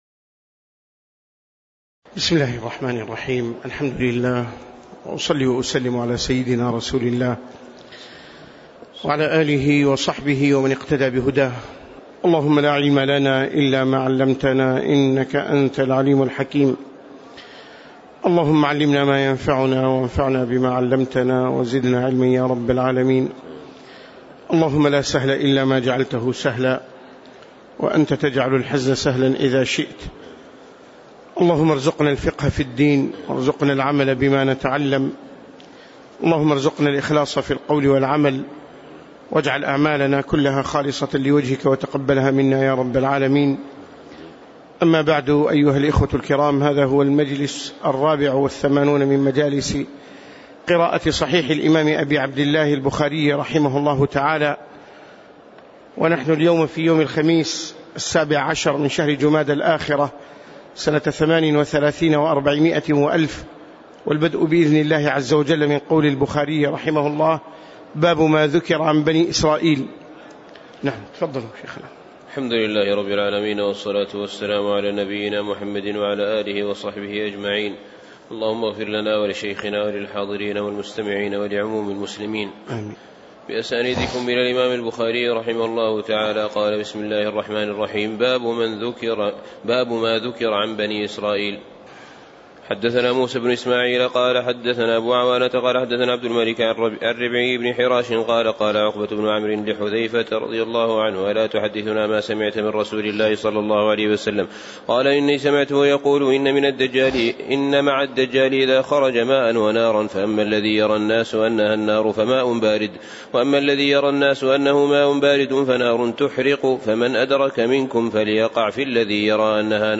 تاريخ النشر ١٧ جمادى الآخرة ١٤٣٨ هـ المكان: المسجد النبوي الشيخ